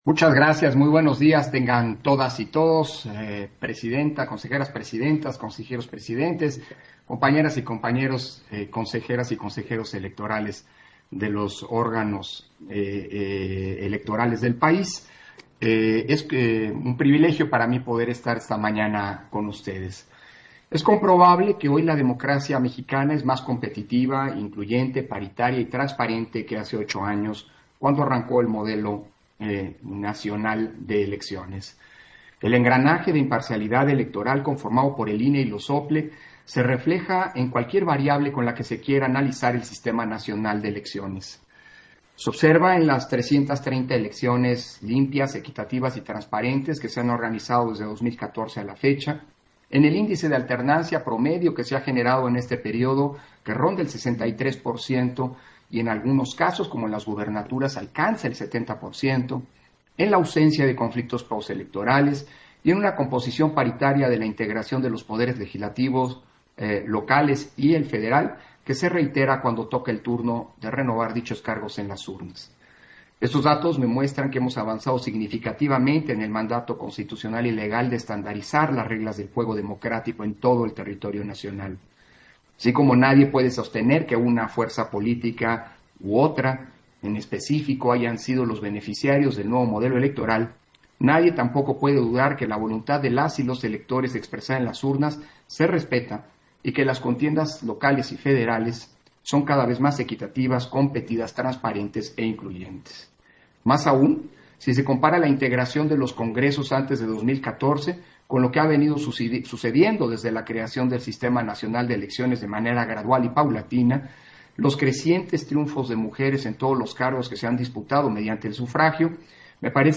Intervención de Lorenzo Córdova, en la inauguración del Quinto Encuentro entre Consejeras y Consejeros del INE y OPL